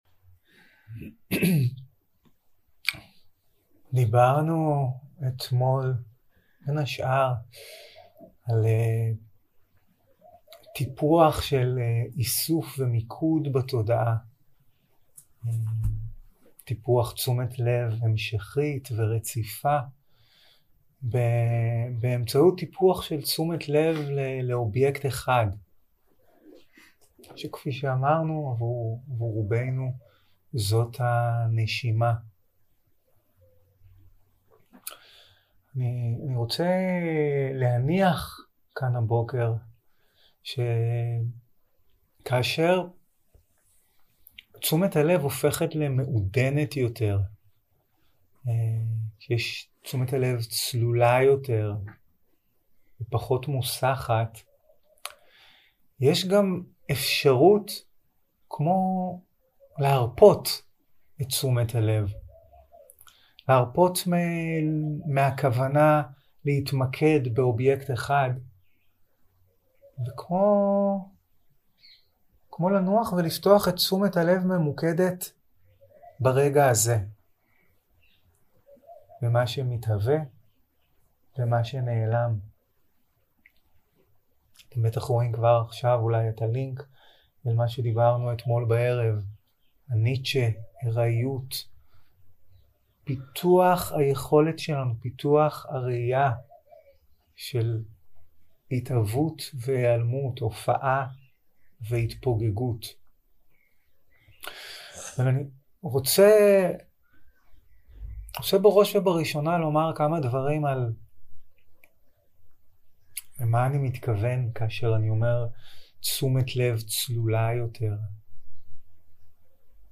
יום 3 - הקלטה 4 - בוקר - הנחיות למדיטציה - תשומת לב הולוגרמית 0:00 0:00 סוג ההקלטה: Dharma type: Guided meditation שפת ההקלטה: Dharma talk language: Hebrew